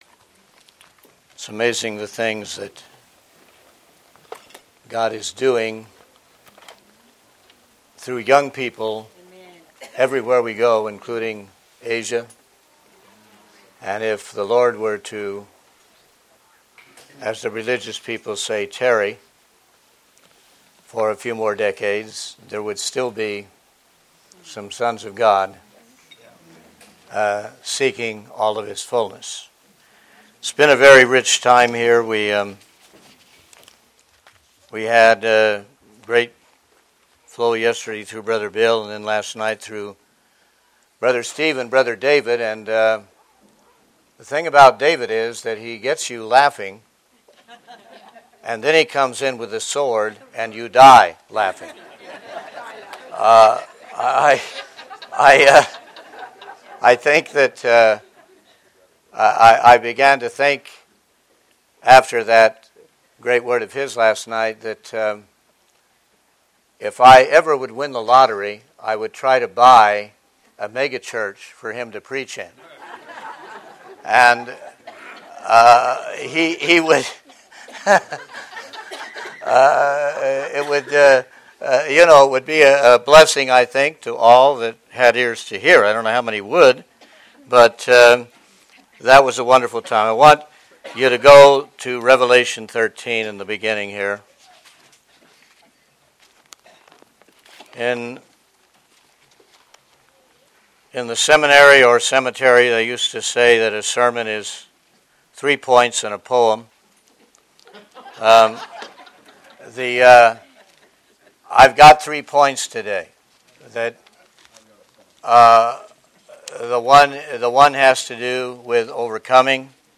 2016 Shepherds Christian Centre Convention